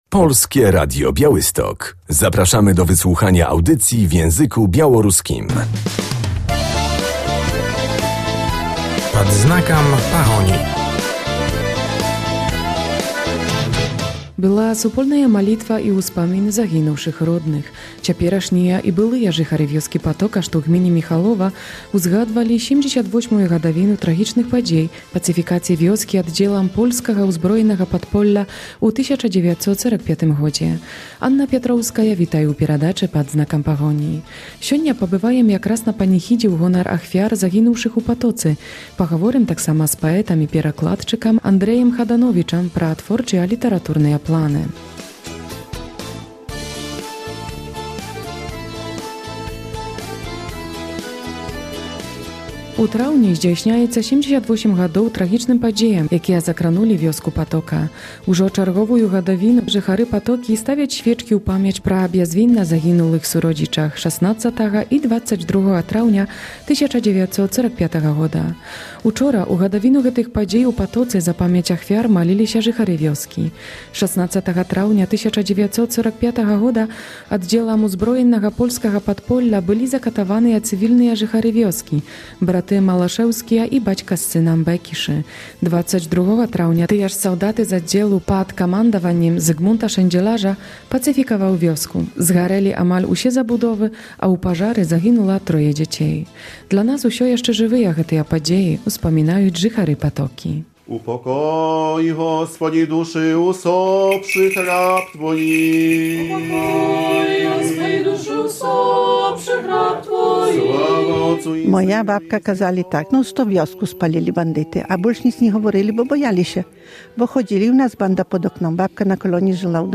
W audycji będziemy na modlitewnych obchodach 78. rocznicy pacyfikacji wsi Potoka.